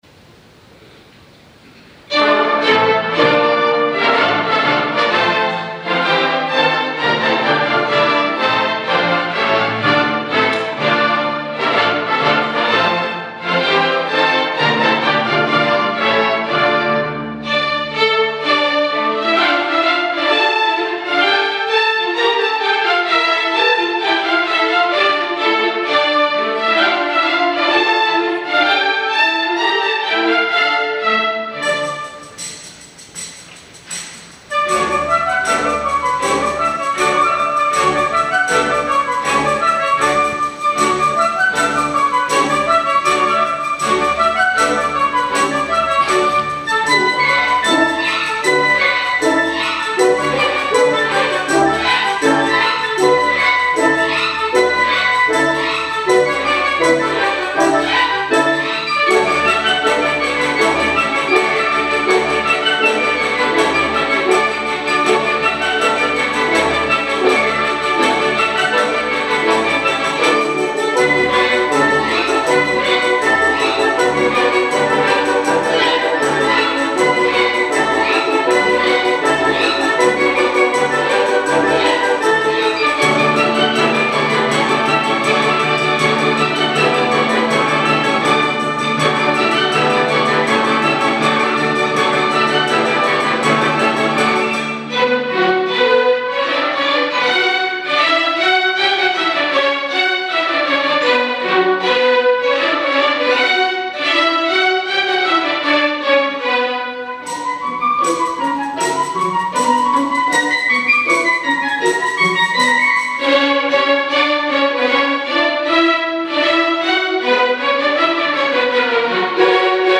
20h - Aula Magna, Louvain-la-Neuve
La première de ces deux œuvres, dressant le tableau d’une sombre soirée italienne, contrastera avec les mélodies joyeuses de la seconde.